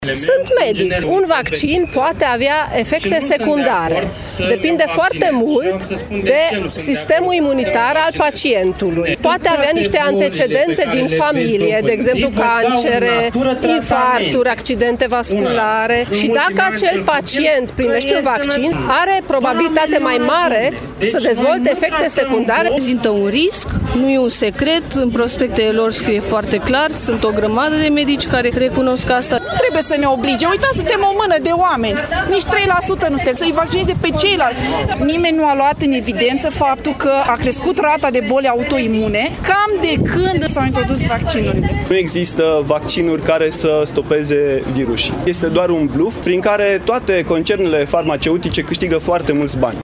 De asemenea, în mun. Brașov peste 500 de participanți în faţa Prefecturii. Oamenii spun că este nedrept să li se impună prin lege să îşi vaccineze copiii:
stiri-8-mar-brasov-vox.mp3